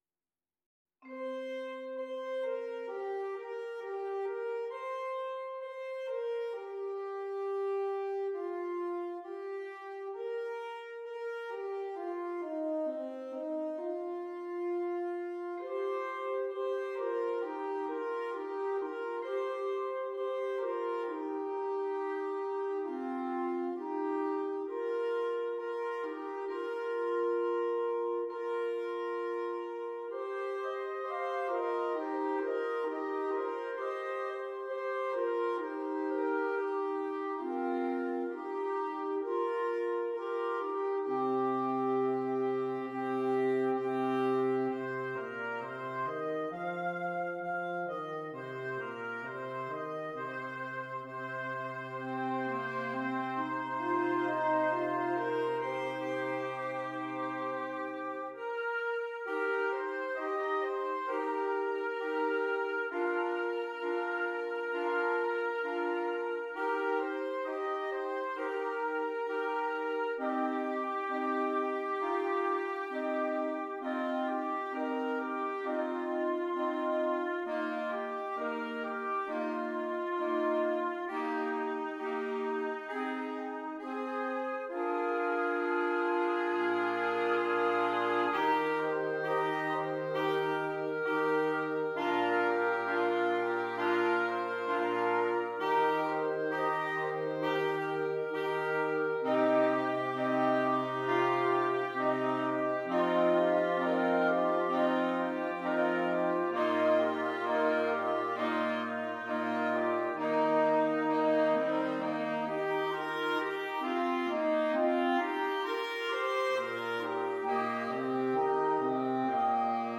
Interchangeable Woodwind Ensemble
PART 1 - Flute, Clarinet, Alto Saxophone
PART 4 - Clarinet, Alto Saxophone, Tenor Saxophone, F Horn
PART 5 - Baritone Saxophone, Bass Clarinet, Bassoon
Percussion (optional) - Vibraphone, Bells